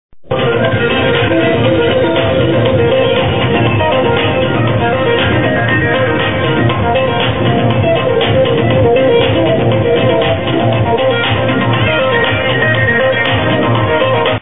Would Like this dance clip song ID`d
this is from a liquid dance clip from houseofrave